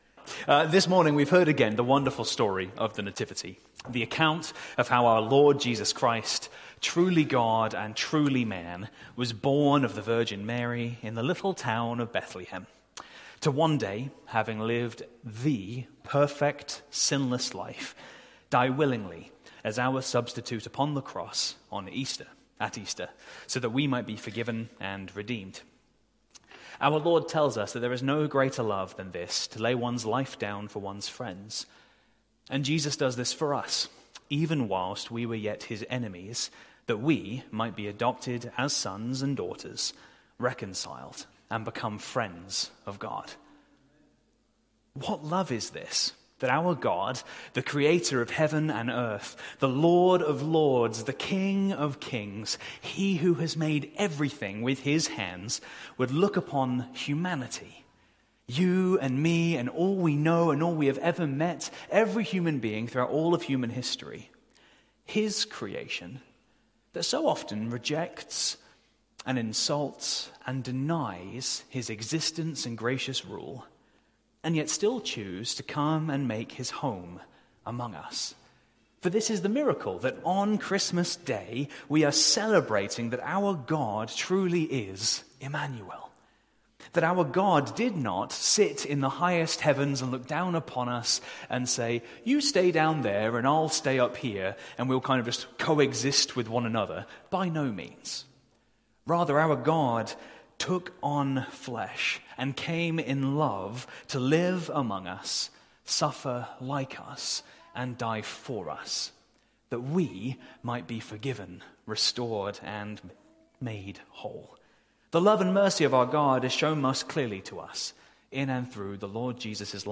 Sermon Series: Advent